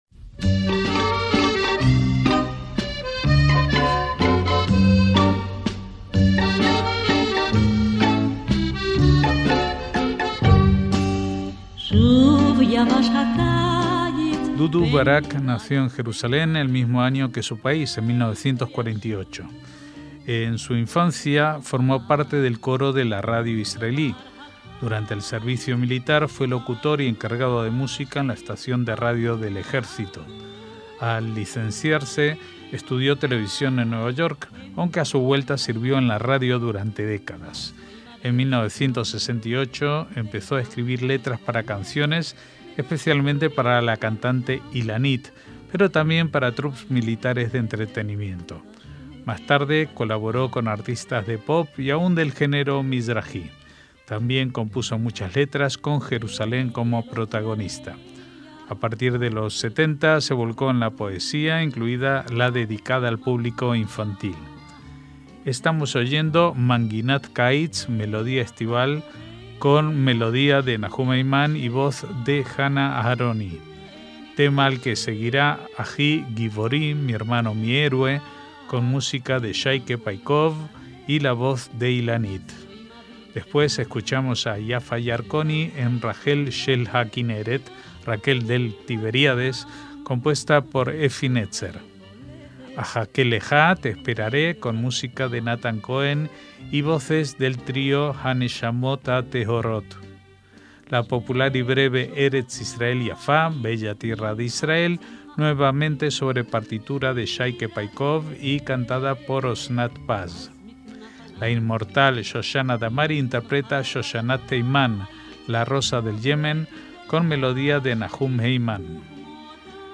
MÚSICA ISRAELÍ - Dudu Barak nació en Jerusalén el mismo año que su país, en 1948.